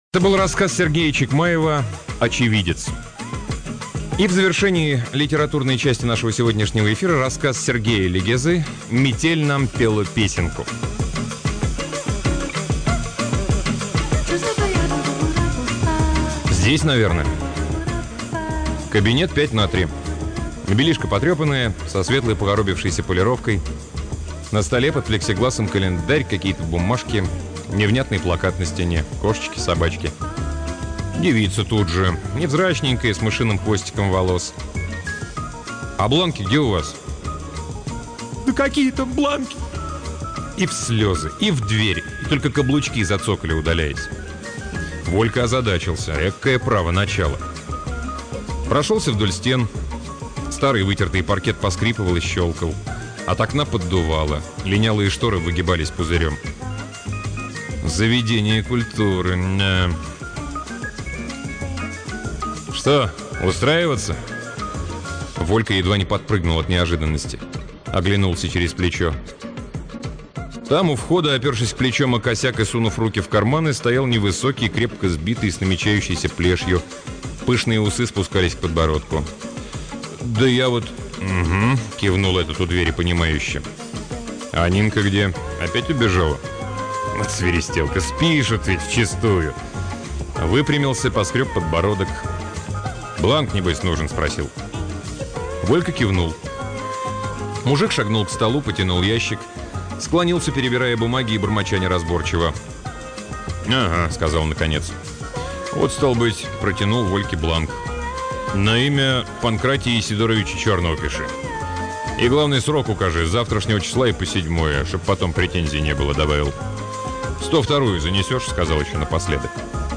Аудиокнига Сергей Легеза — Метель нам пела песенку